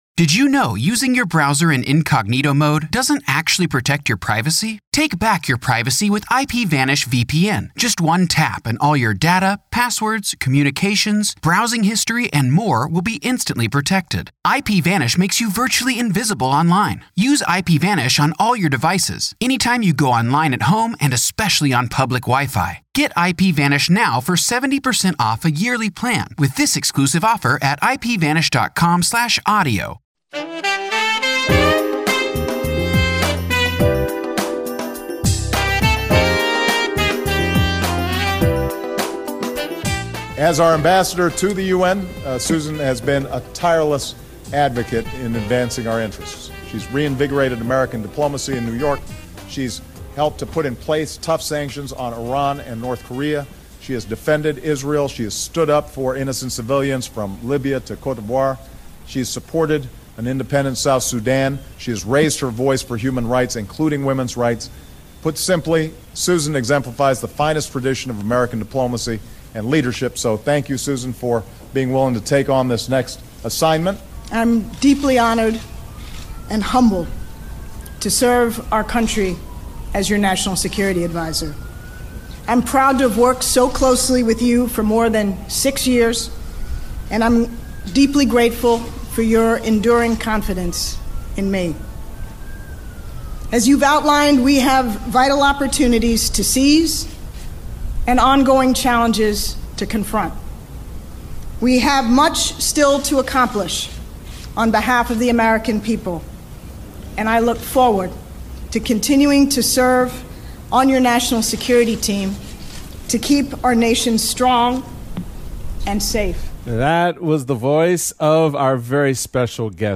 Susan Rice reveals all in her conversation with Michael Steele.